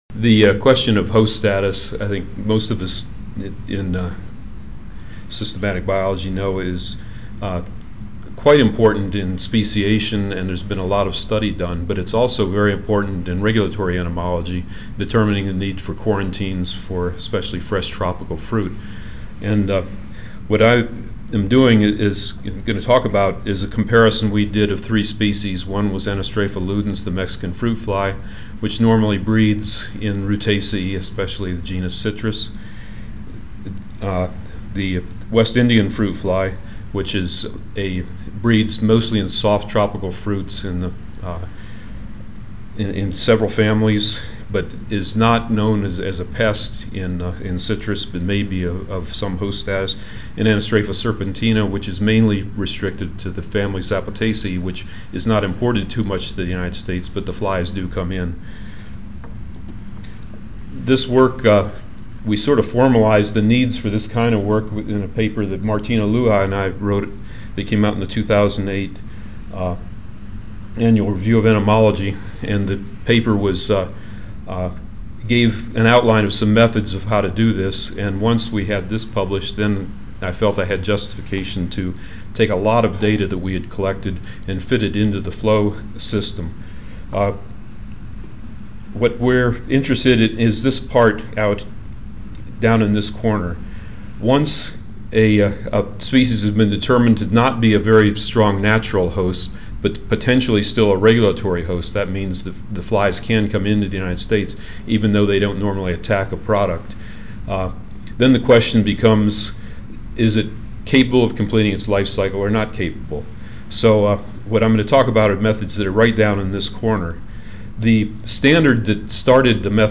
Room A12, First Floor (Reno-Sparks Convention Center)
Ten Minute Paper (TMP) Oral